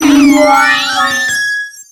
Cri de Togekiss dans Pokémon X et Y.